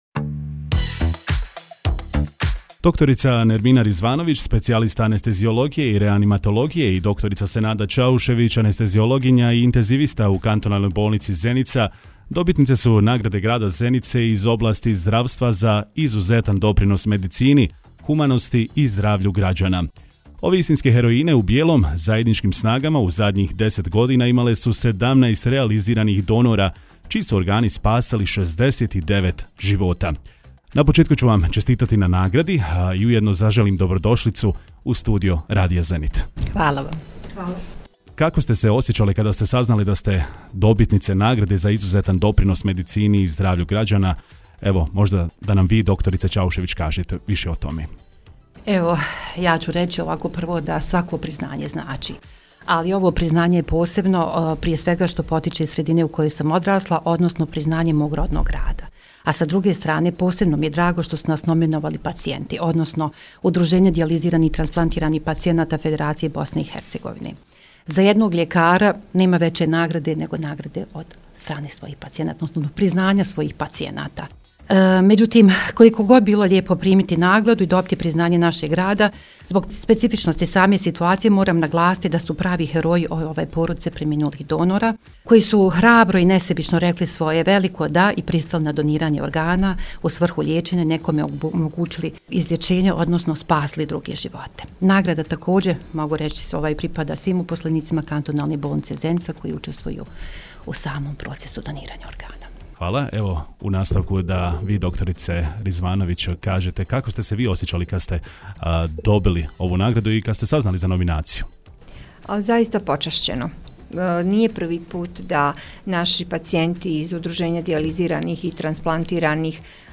Gostujući u studiju radija Zenit